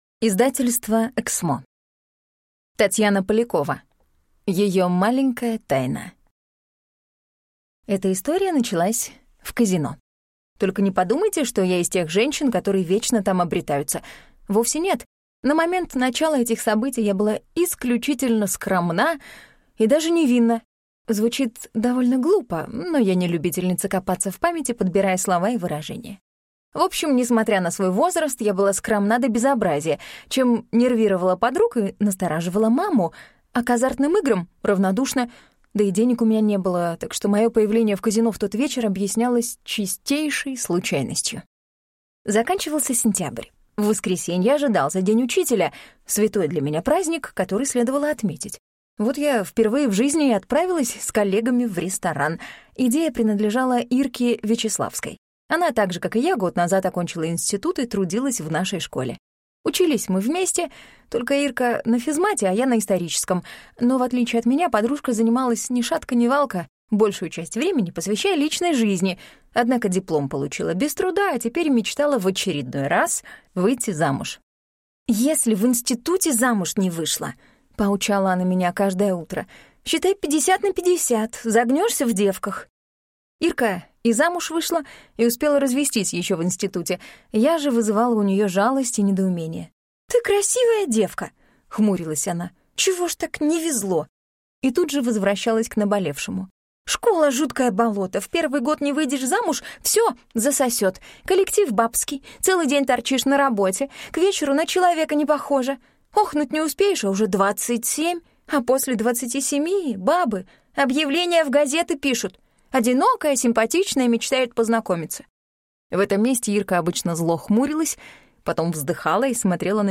Аудиокнига Ее маленькая тайна | Библиотека аудиокниг